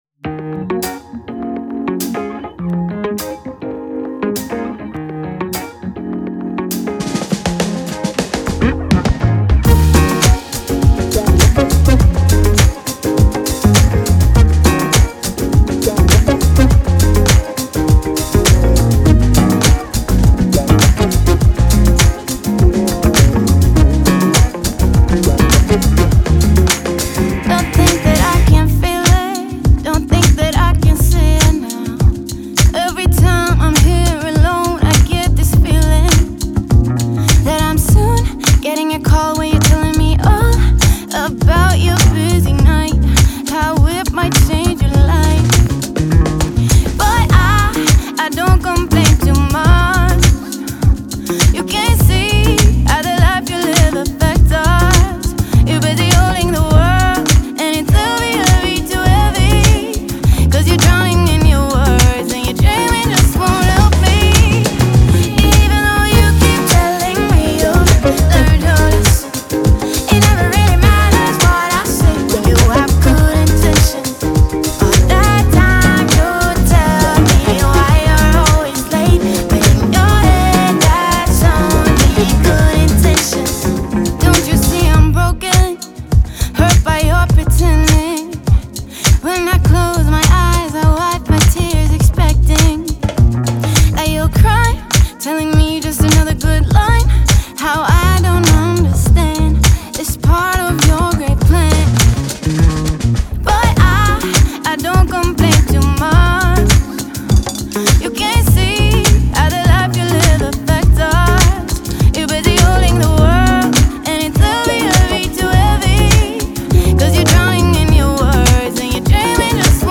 BPM102-102
Audio QualityPerfect (High Quality)
Neo Soul/Funk song for StepMania, ITGmania, Project Outfox
Full Length Song (not arcade length cut)